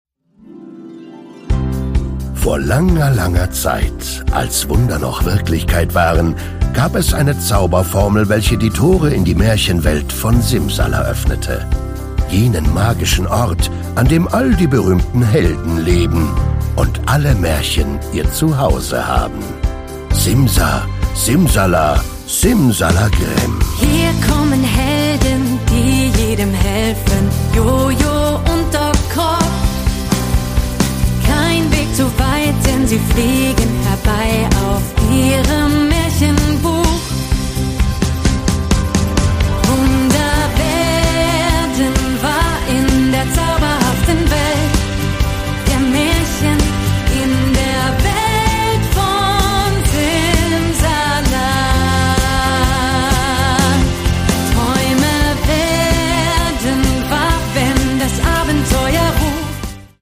Das Original-Hörspiel zur TV Serie
Produkttyp: Hörspiel-Download